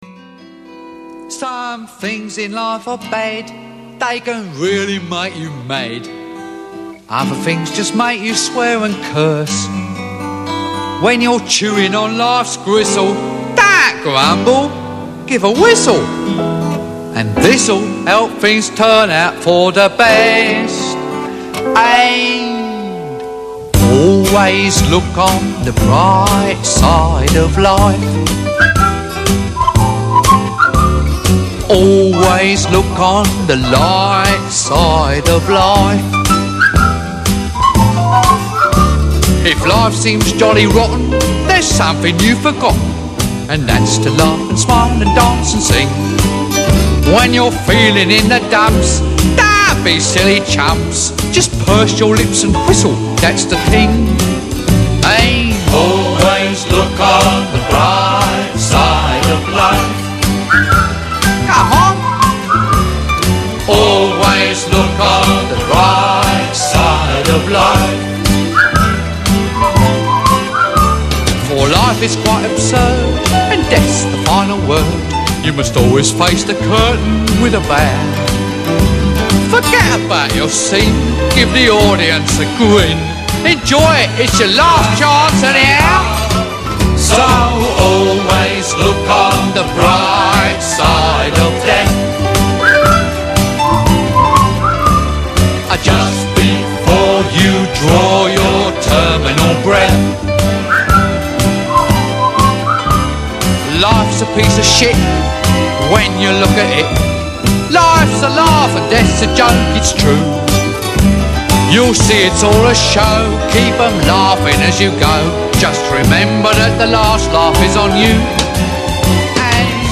Estudioko dardar elektrostatikoaren zirrara sentitzea.